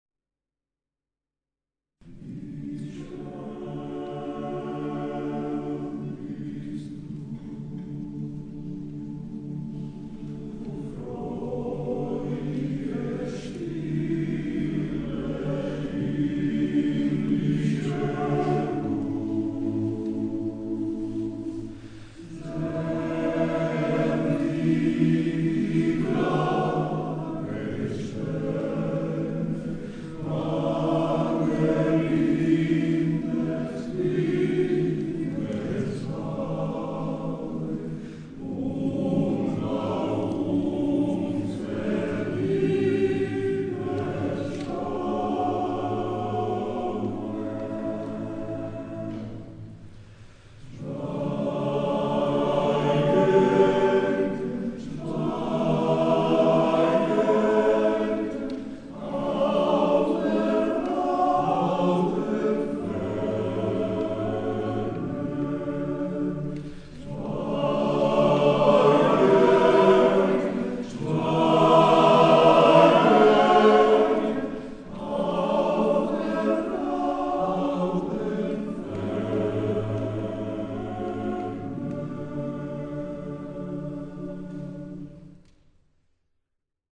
Coro di Breganze